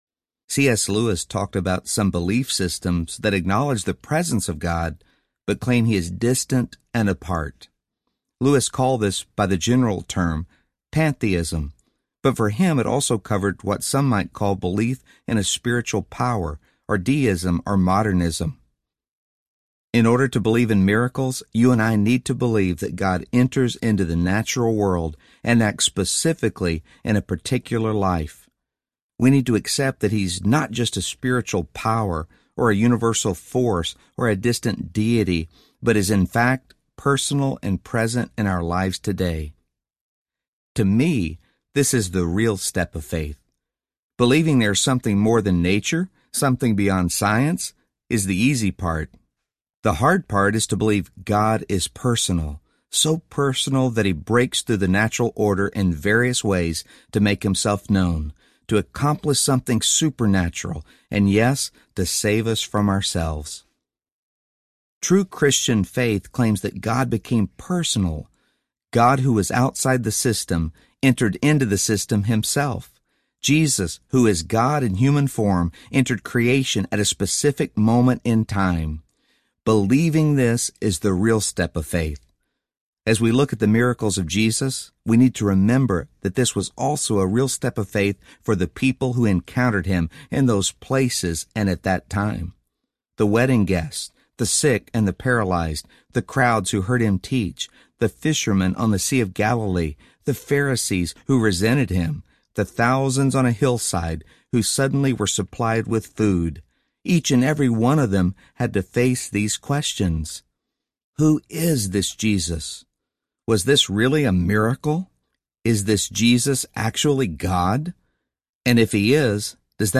Find Your Miracle Audiobook